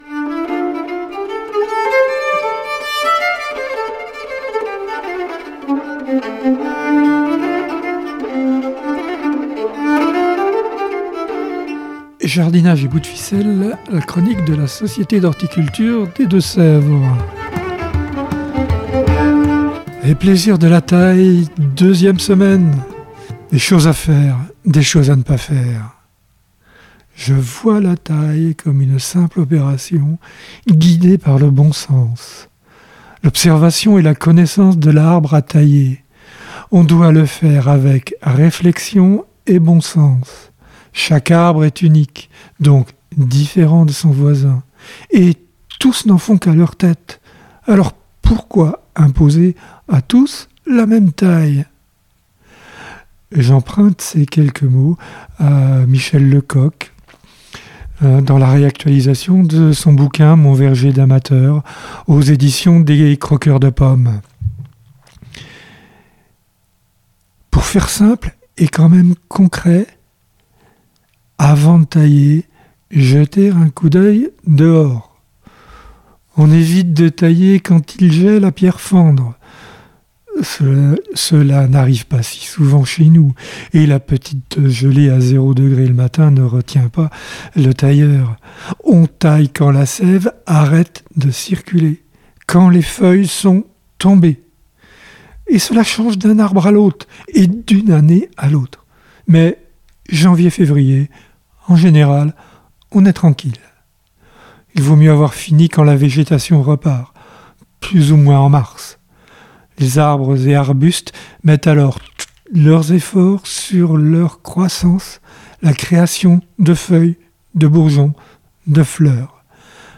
(Ces chroniques sont diffusées chaque semaine sur les radios D4B et Pigouille Radio)